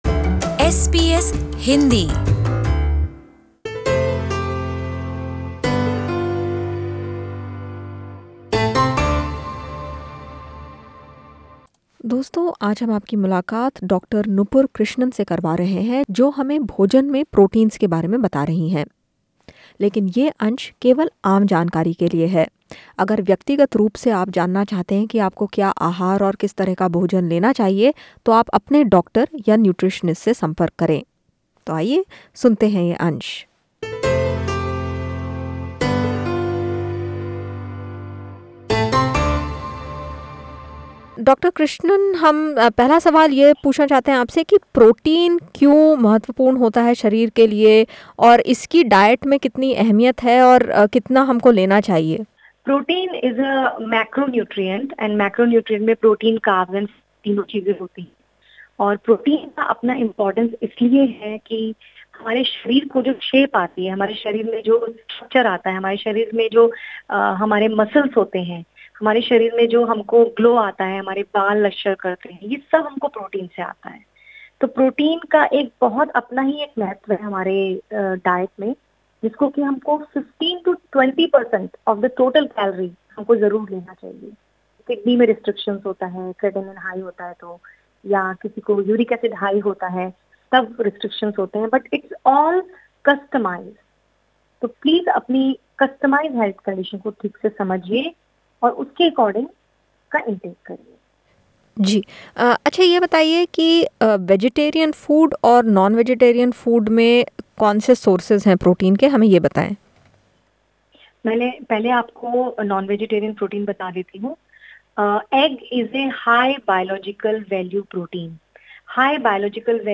(This interview is for general information only, please consult your own GP or nutritionist to understand your individual nutritional needs.)